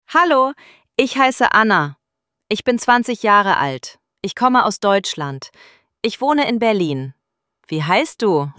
ElevenLabs_Text_to_Speech_audio-36.mp3